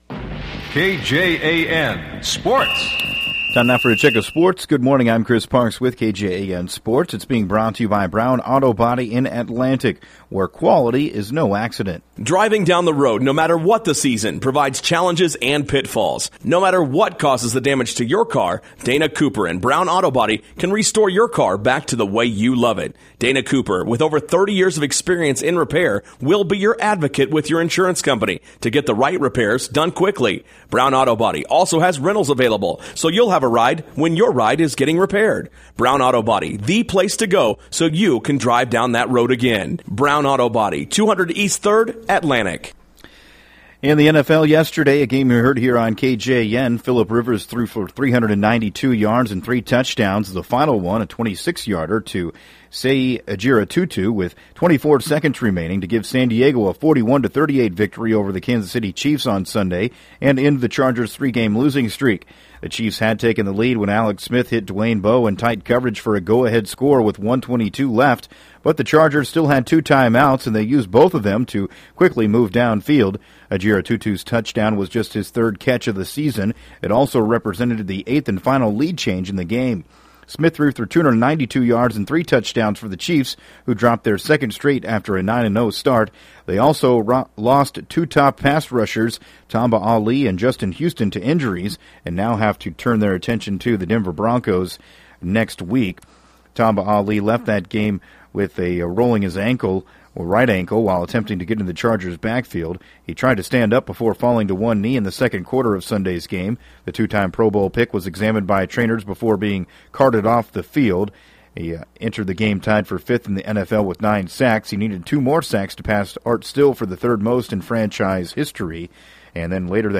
have the call of the games played at Adair-Casey High School.